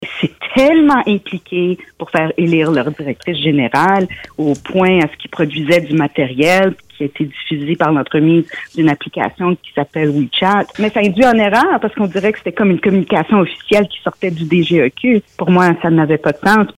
En entrevue sur nos ondes ce mercredi, Mme Assaad est revenue sur des événements survenus lors de la dernière campagne électorale municipale.